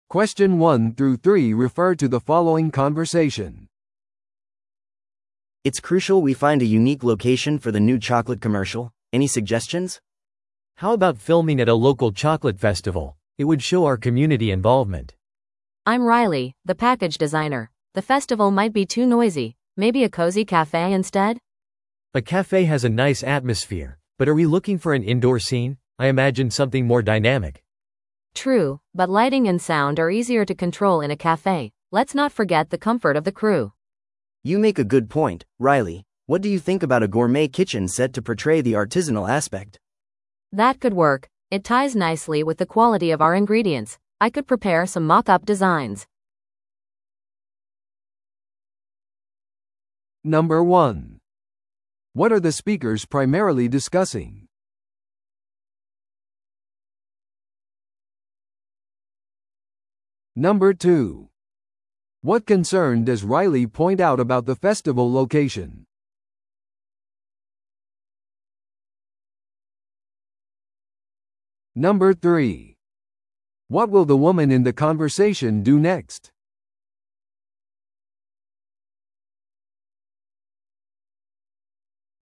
TOEICⓇ対策 Part 3｜新製品コマーシャルの撮影場所検討 – 音声付き No.298